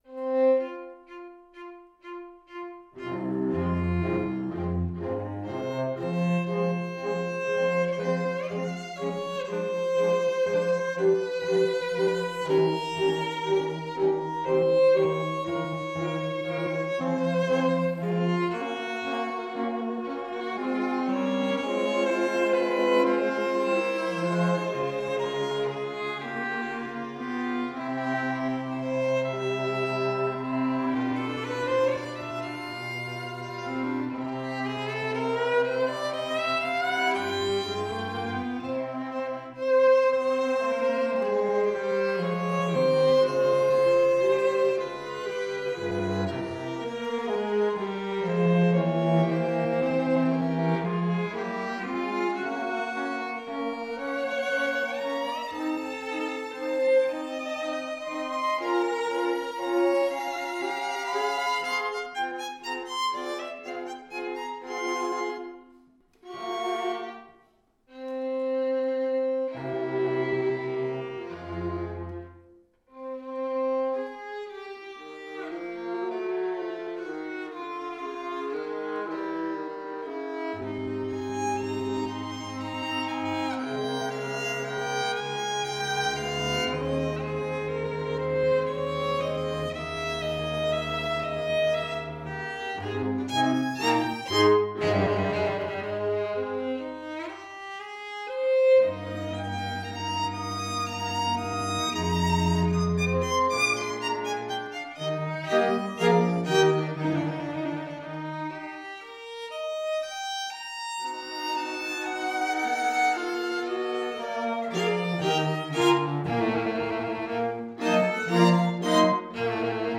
Soundbite 3rd Movt
In third place is a pulsing Andante con moto.